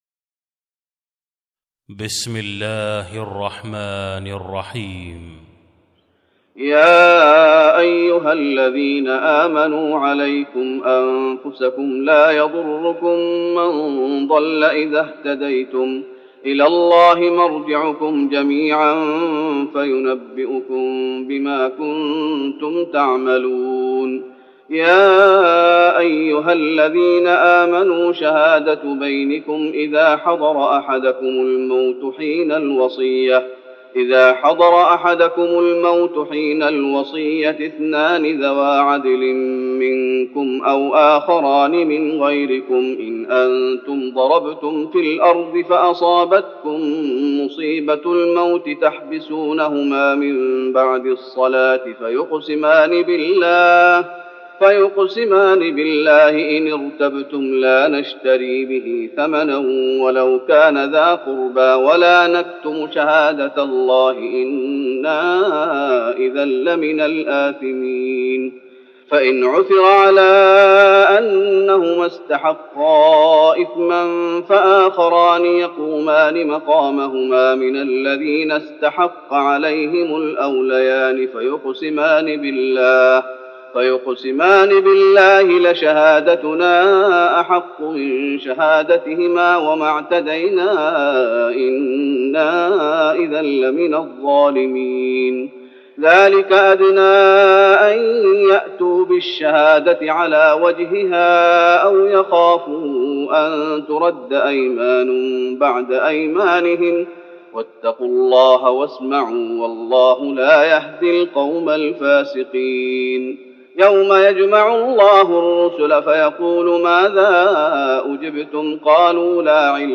تراويح رمضان 1413هـ من سورة المائدة (105-120) Taraweeh Ramadan 1413H from Surah AlMa'idah > تراويح الشيخ محمد أيوب بالنبوي 1413 🕌 > التراويح - تلاوات الحرمين